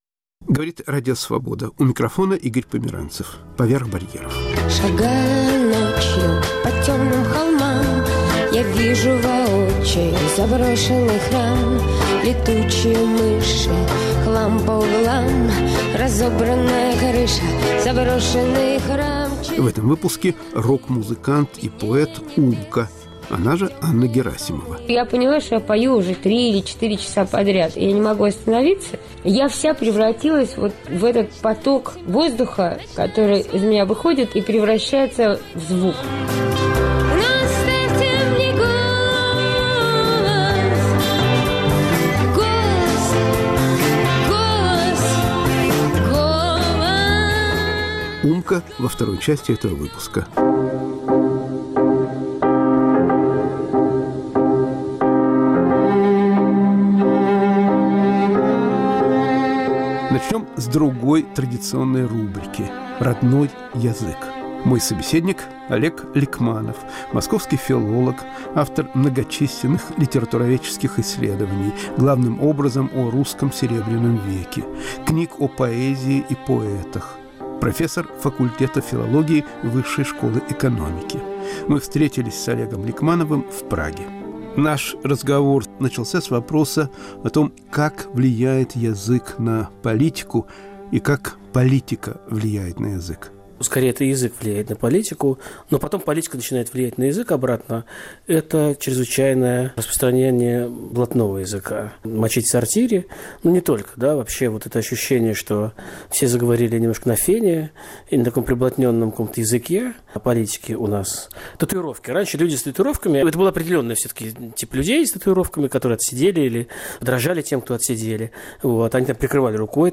В передаче участвуют реставратор, историк культуры и театральный режиссёр.*** «Мои любимые пластинки» с рок-музыкантом Умкой.